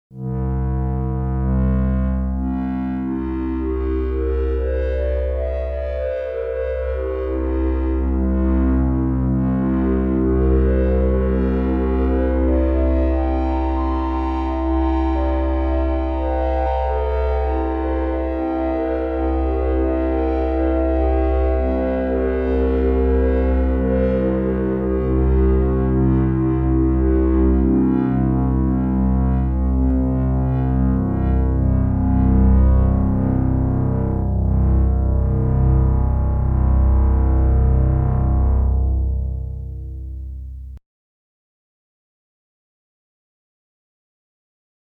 Tous ces sons ont été enregistrées directement en sortie du DX7, donc sans aucun effet : ni reverb, ni chorus.
HarmoniqAC p.169 : ce son est fait pour ne jouer que les harmoniques de DO. L’instrument pourrait être une une grande feuille métallique que l’on joue par frottements (ça existe et ça donne à peu près ce genre de son).